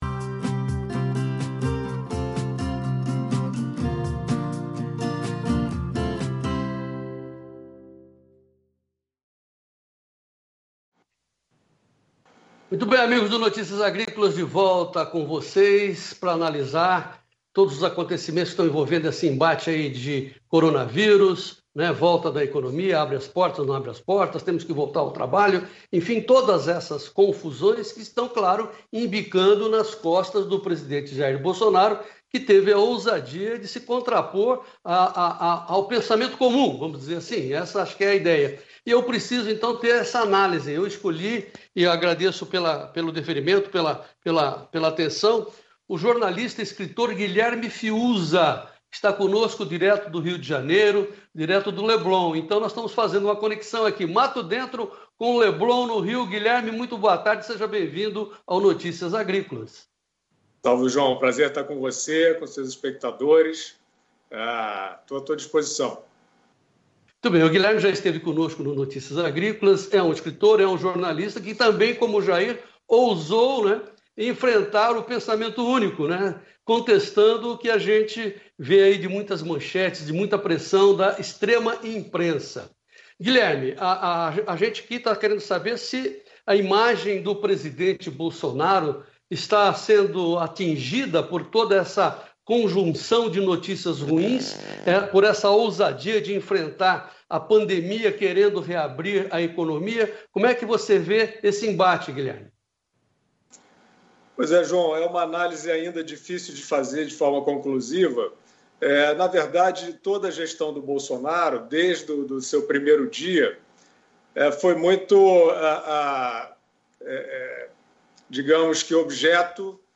Entrevista com Guilherme Fiuza - Jornalista e Escritor sobre a Imagem de Bolsonaro está arranhada?